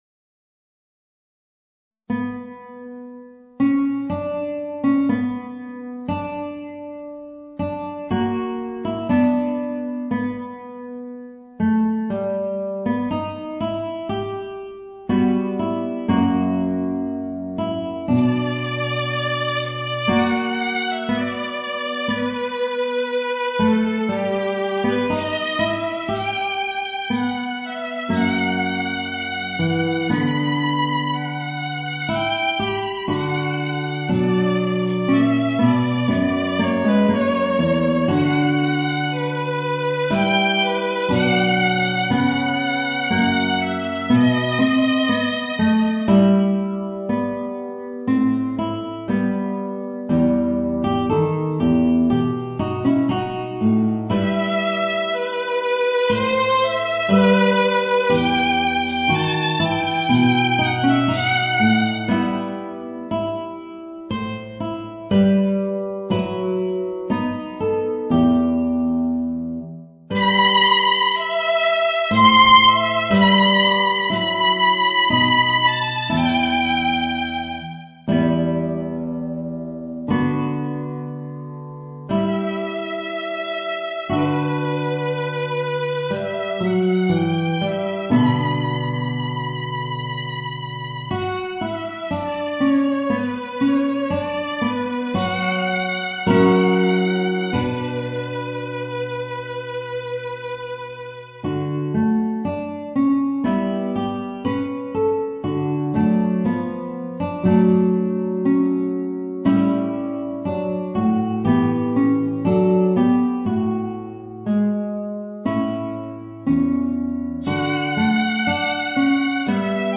Voicing: Guitar and Violin